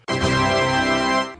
Play Victory Sound - SoundBoardGuy
victory-sound.mp3